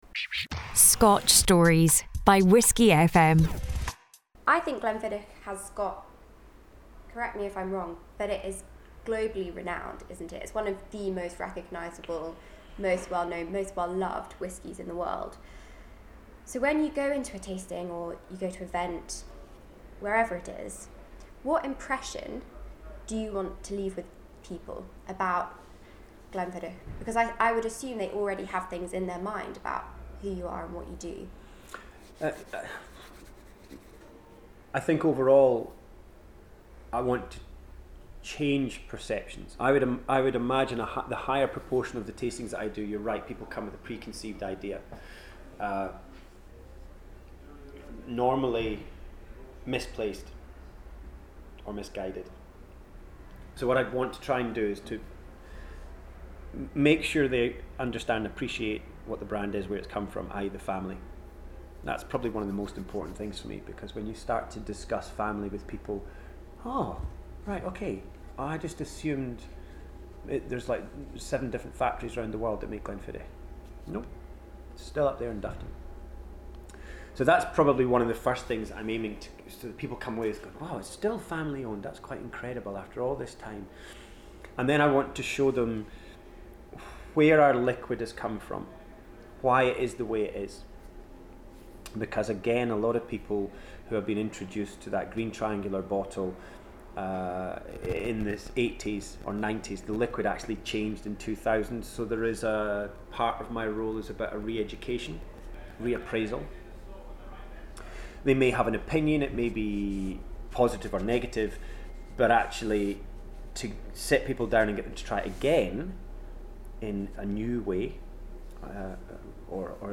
Glenfiddich Interview